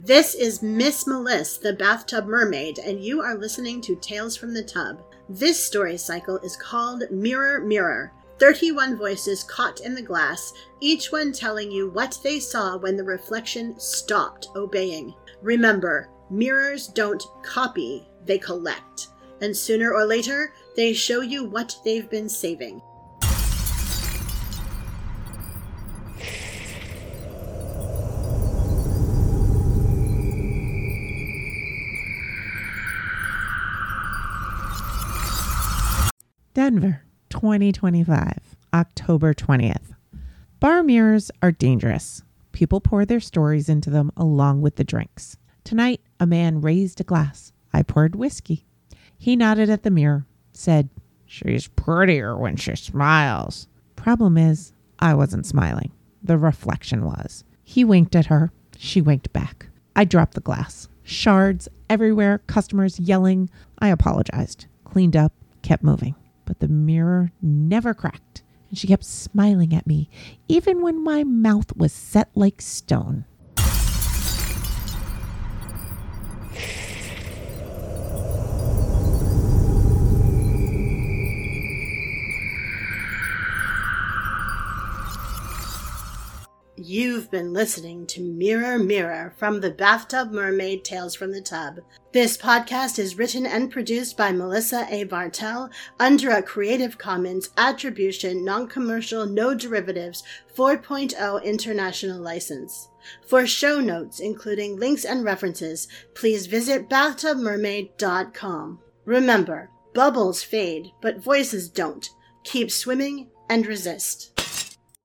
Bathtub Mermaid blog header art was created by Rebecca Moran of Moran Media Sound Effects are from Freesound.